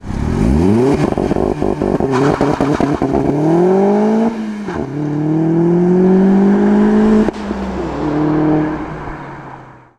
Soundfile einer Beschleunigung aus dem Stillstand heraus mit Launch Control
Opel Speedster Turbo 2.0